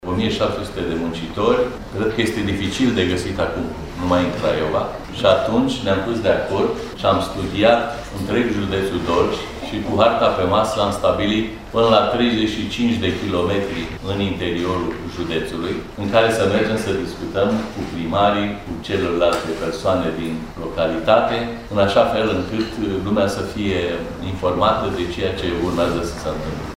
16:46 Conducerea Companiei Ford România se va întâlni săptămâna viitoare cu primarii localităţilor din judeţul Dolj aflate pe o rază de până la 35 kilometri de Craiova – acolo unde se află uzina auto – pentru a-i informa că, în viitorul apropiat, vor începe angajările pentru producerea unui nou model, pentru care se vor crea 1.700 de locuri de muncă. De ce este nevoie de aceste discuţii, a explicat preşedintele Consiliului Judeţean Dolj, Ion Prioteasa: